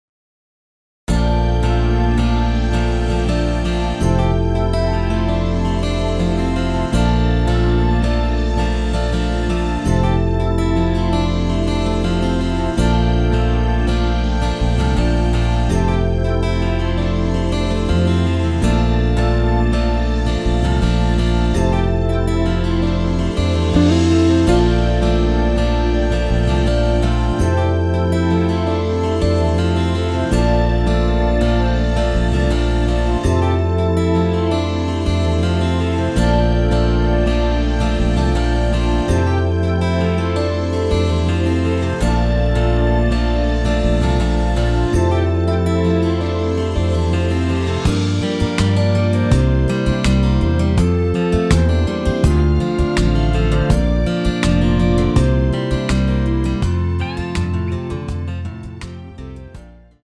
엔딩이 페이드 아웃이라 라이브 하시기 좋게 엔딩을 만들어 놓았습니다.